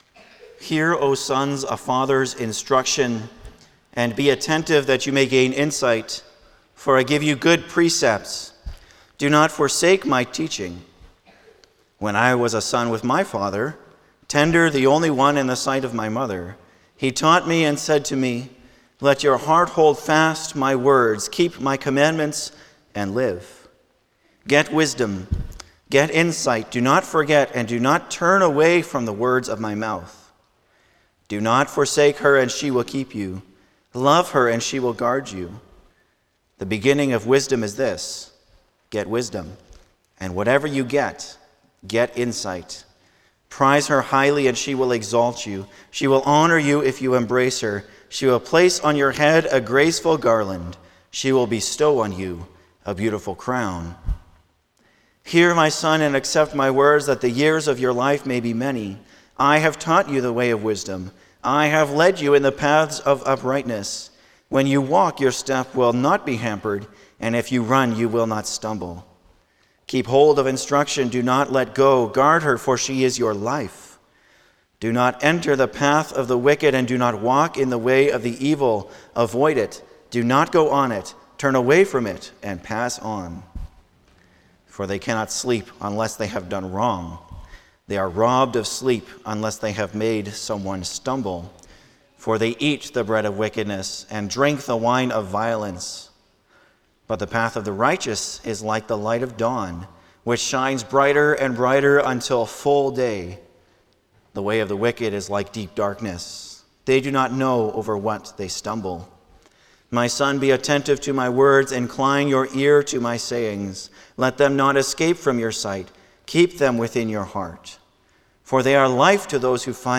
Service Type: Sunday morning
07-Sermon.mp3